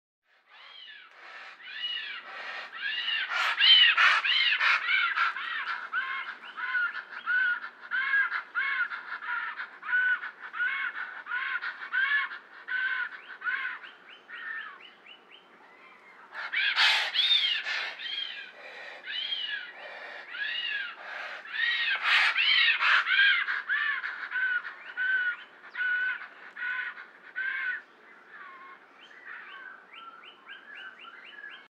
Звук птерозавра